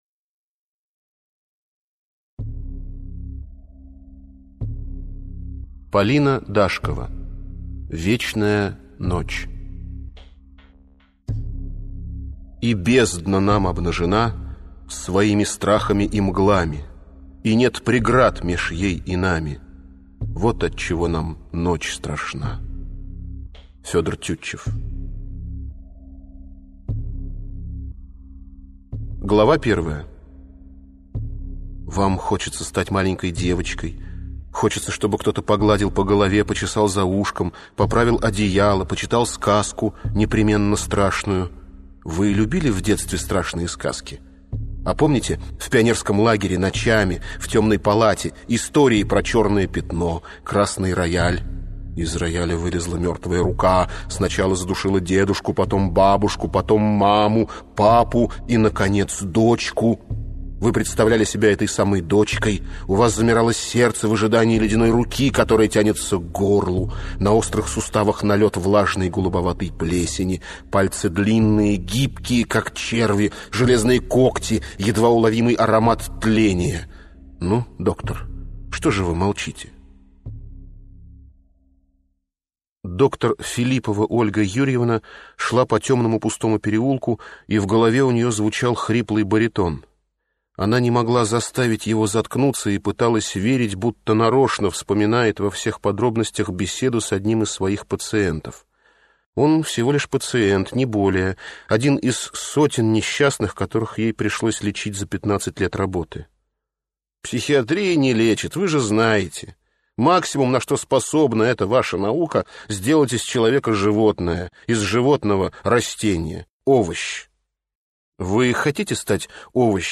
Аудиокнига Вечная ночь | Библиотека аудиокниг